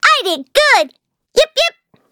Taily-Vox_Victory.wav